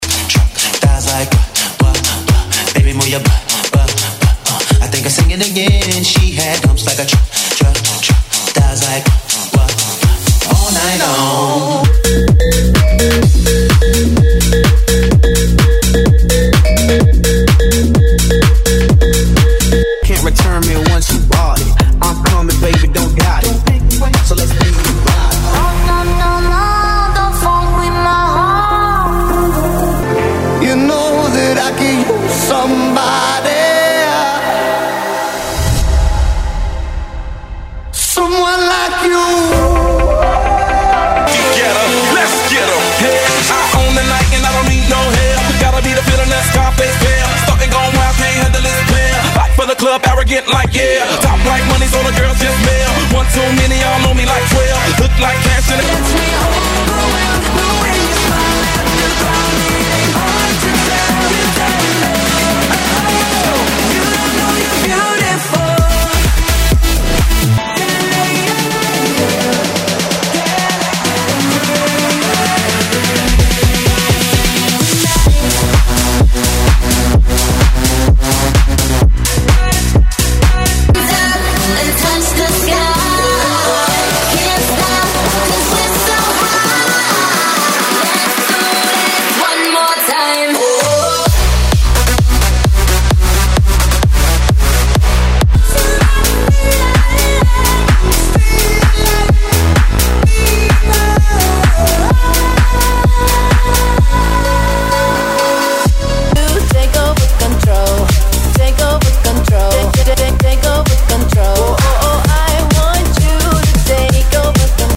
BPM: 124 Time